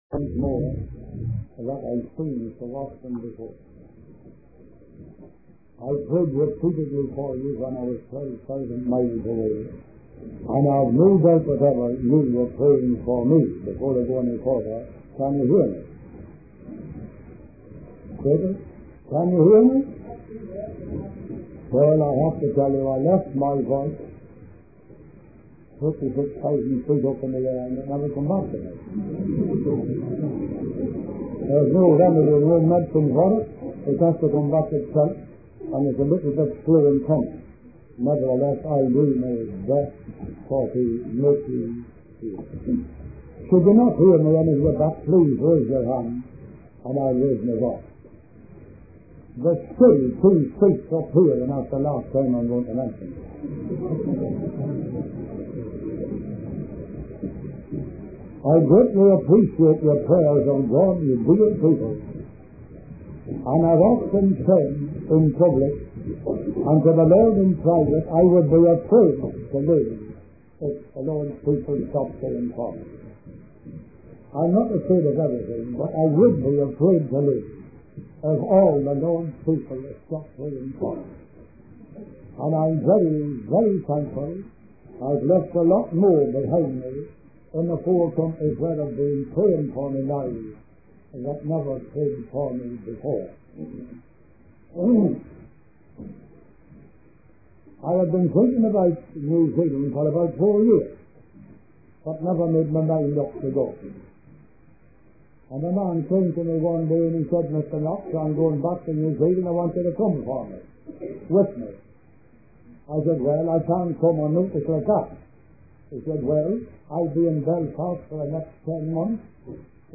In this sermon, the speaker recounts his experiences visiting different assemblies and witnessing the poverty and struggles faced by the people. He emphasizes the importance of showing mercy and goodness towards those in need. The speaker also mentions the lack of unity among believers and the need for more assemblies to come together.